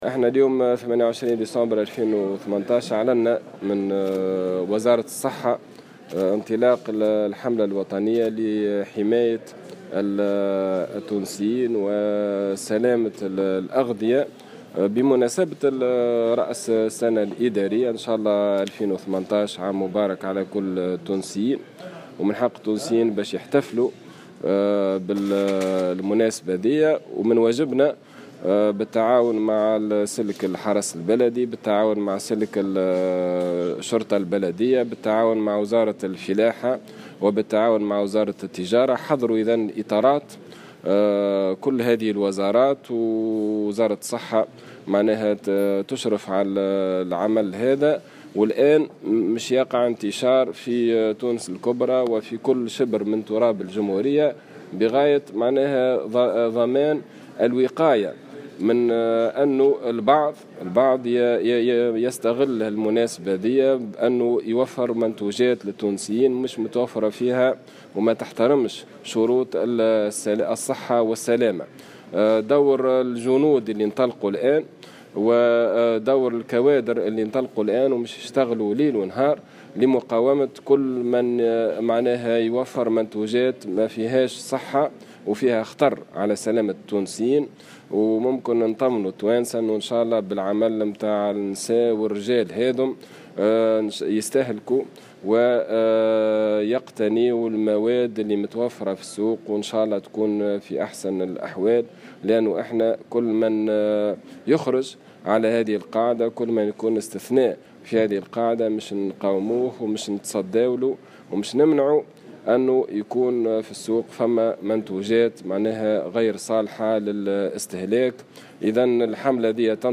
انطلقت اليوم الخميس 28 ديسمبر 2017، حملة وطنية لمراقبة المرطبات بمناسبة رأس السنة الإدارية، في كافة ولايات الجمهورية، حسب ما أعلنه وزير الصحة عماد الحمامي في تصريح لمراسل الجوهرة اف ام.